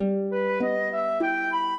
minuet12-10.wav